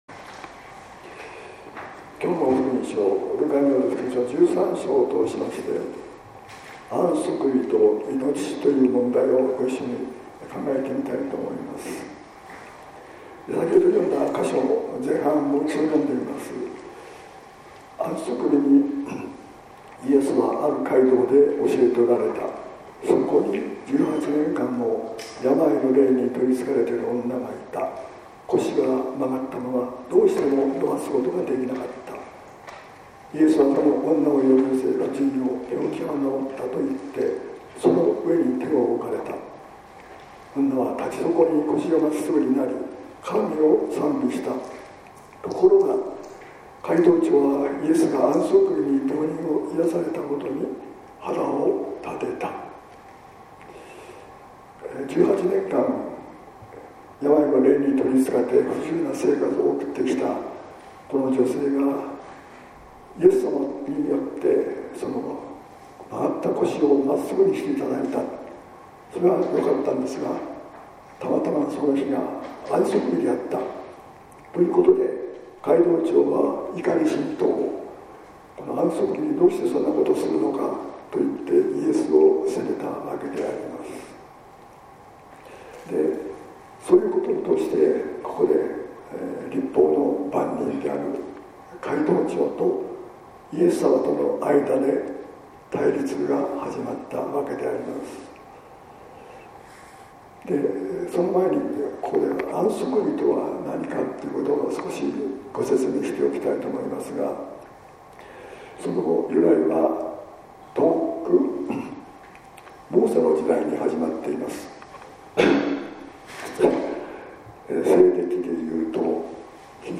説教「安息日といのちの問題」（音声版）